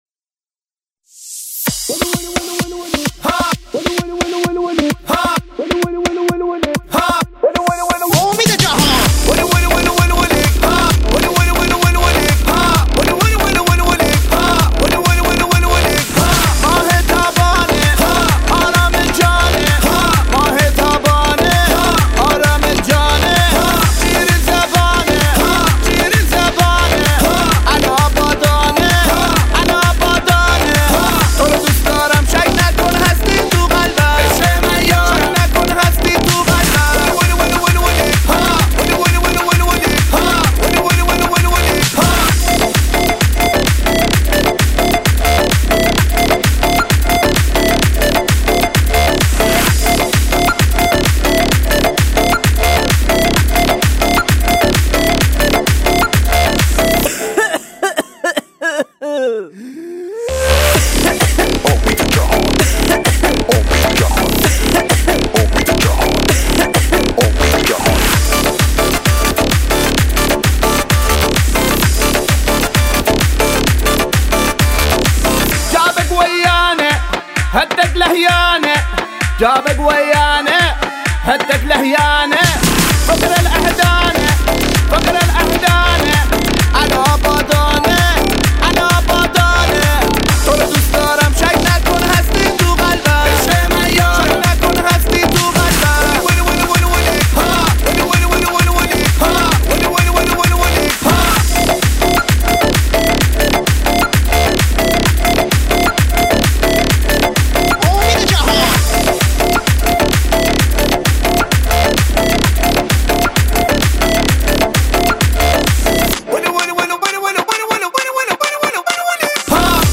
دانلود آهنگ شاد با کیفیت ۱۲۸ MP3 ۴ MB